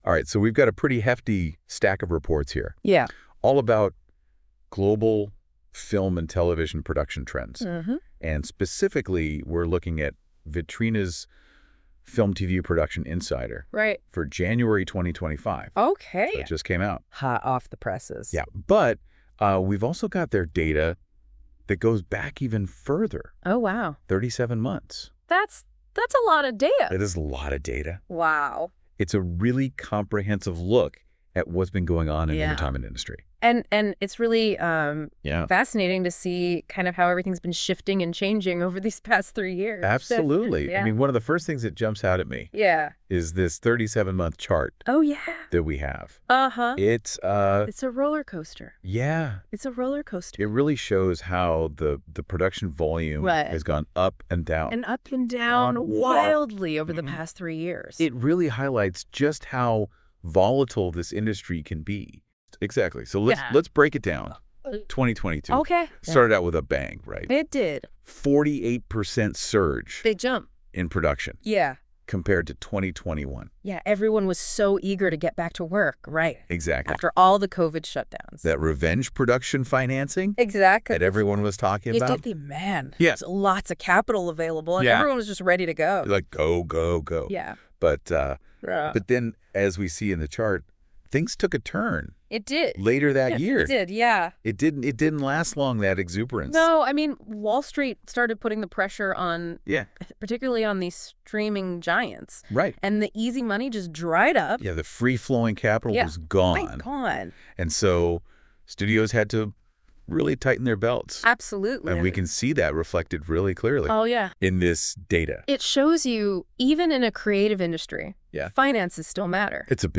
The Global Monthly Film + TV Production Report is curated by Vitrina experts with insights from our network. The audio podcast was generated with Deep Dive and reviewed by our team.